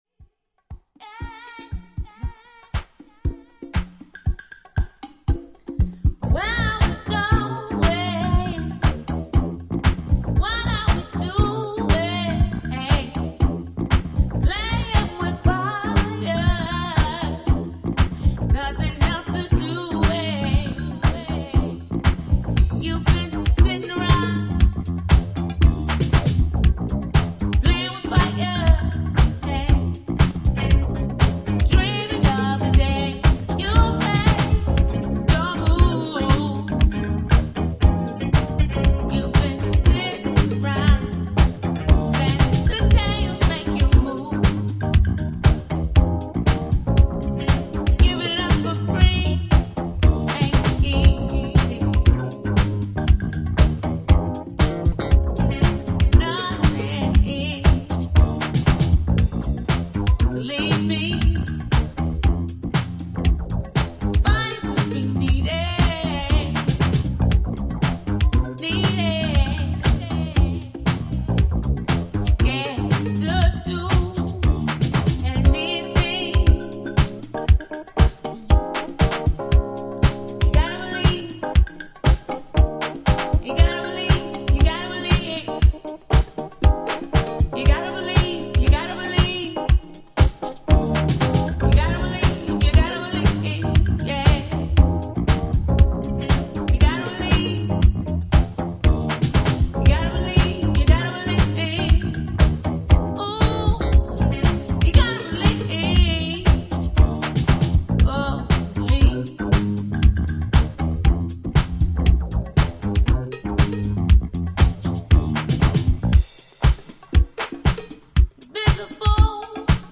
vocalist extraordinaire
House